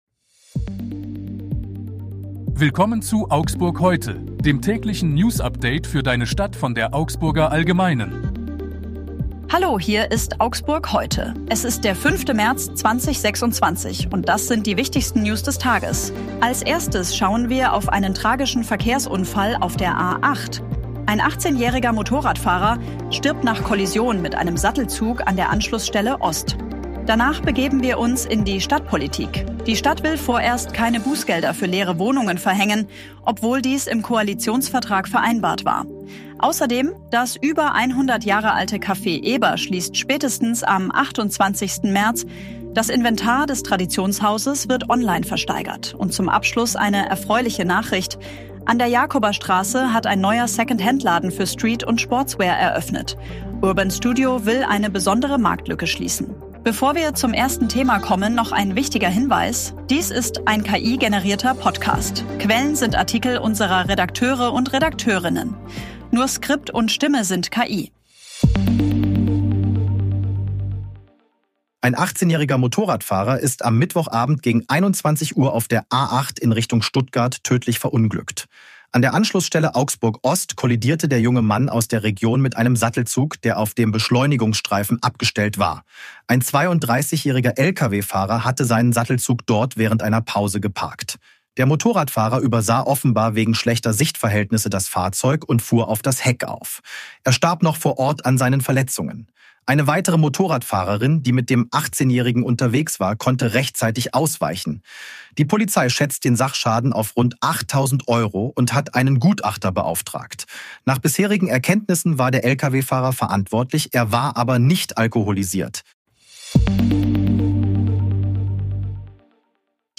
Stimme sind KI.